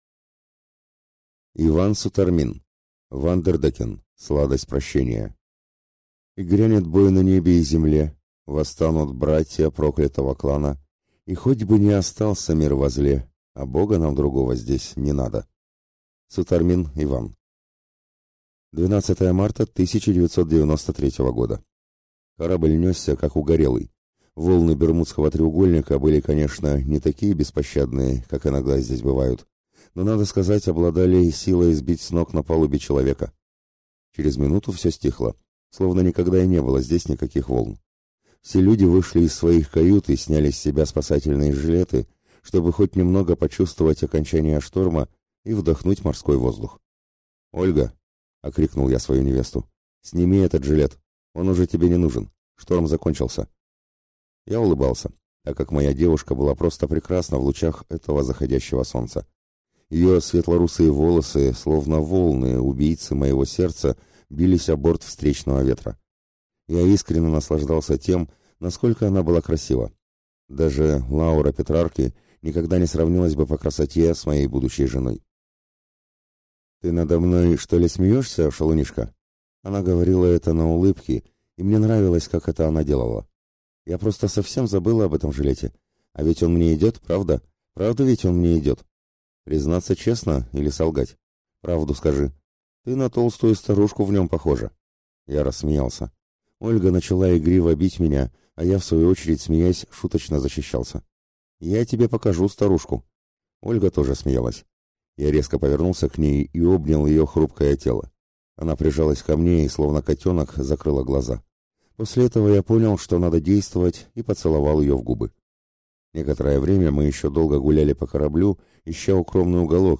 Аудиокнига Ван дер Деккен. Сладость прощения | Библиотека аудиокниг